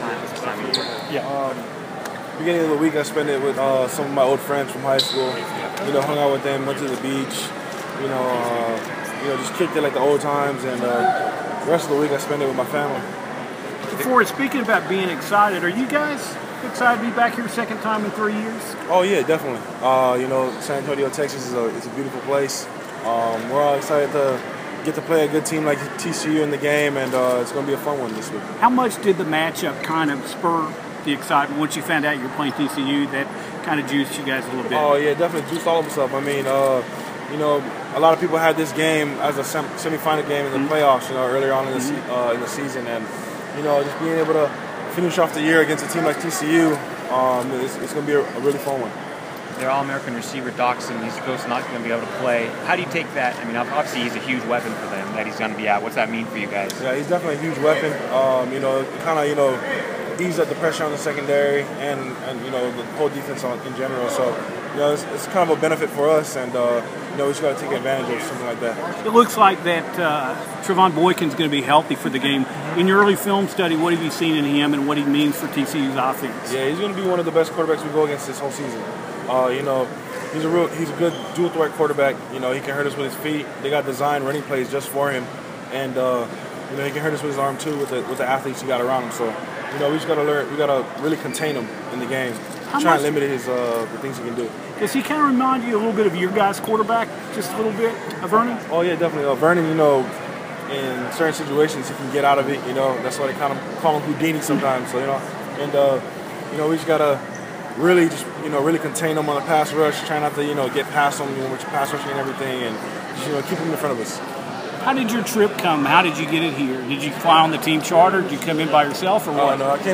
Oregon Team Arrival – DeForest Buckner Interview
Oregon Defensive End DeForest Buckner at Oregon Team Arrival